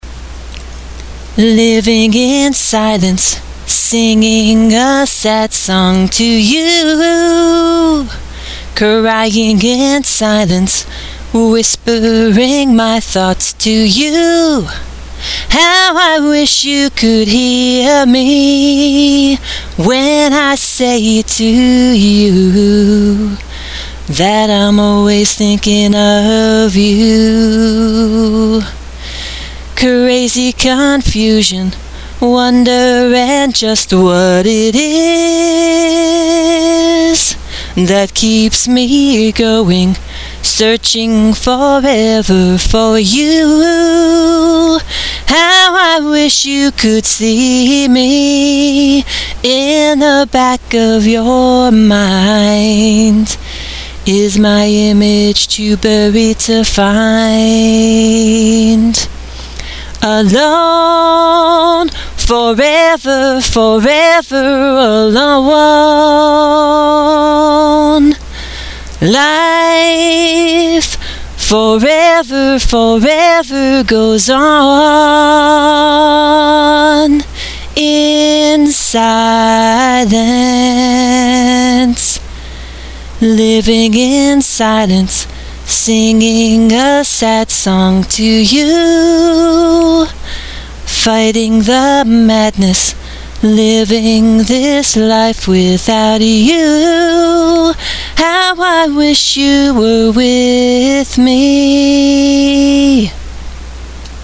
Unproduced a cappella - 2011